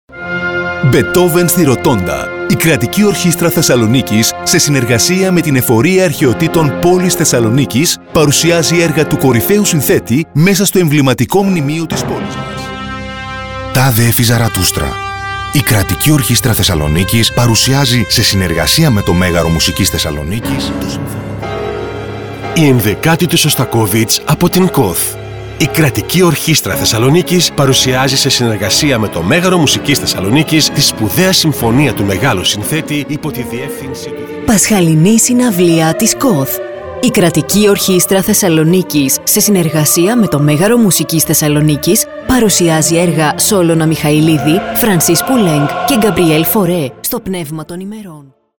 Η συνεργασία μας με την Κρατική Ορχήστρα Θεσσαλονίκης κρατάει σχεδόν 10 χρόνια!Ολα αυτό το διάστημα εκφωνήσαμε και κάναμε post production σε εκατοντάδες ραδιοφωνικά και τηλεοπτικά σποτ για τις σπουδαίες συναυλίες της με διάσημους σολίστ που φιλοξενούνται στο Μέγαρο Μουσικής Θεσσαλονίκης, και σε άλλους σημαντικούς συναυλιακούς χώρους υψηλού επιπέδου.